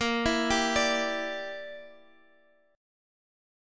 A#6b5 chord